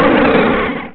Cri de Relicanth dans Pokémon Rubis et Saphir.
Cri_0369_RS.ogg